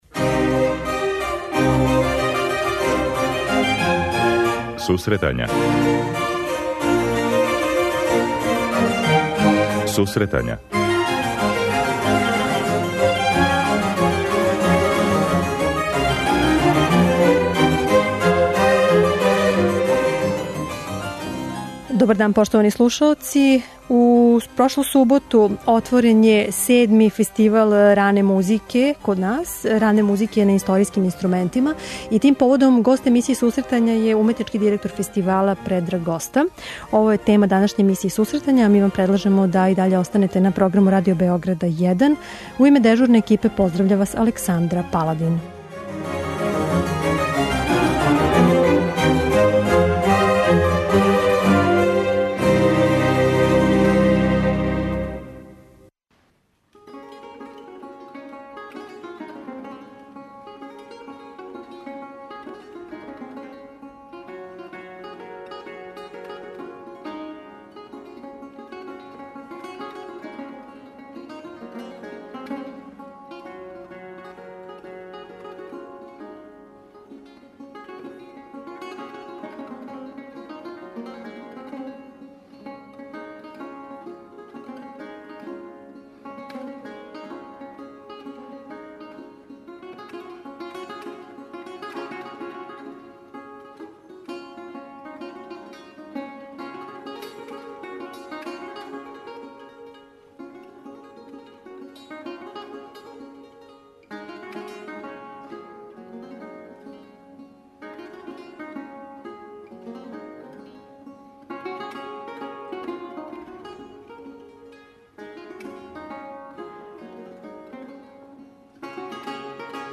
Гост емисије је диригент